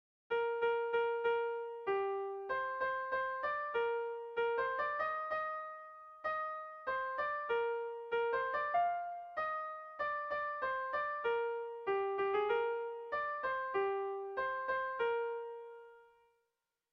Erromantzea
AB